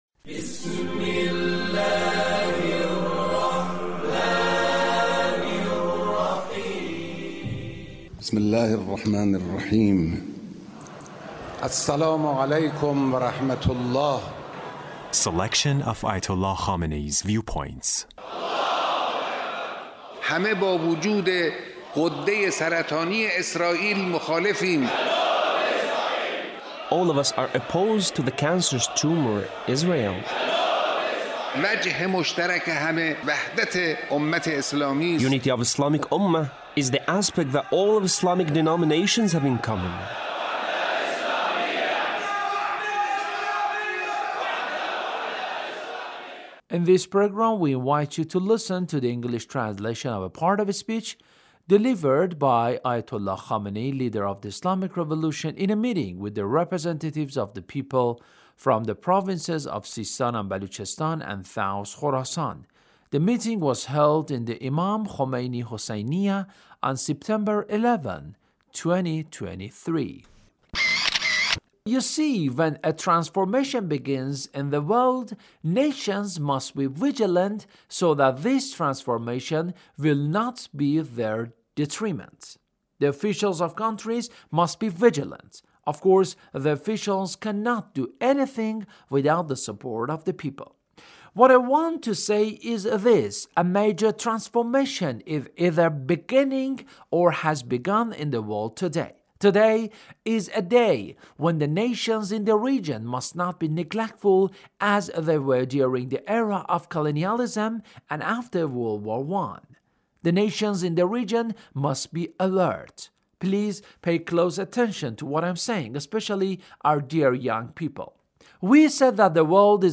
Leader's Speech with the people of SISTAAN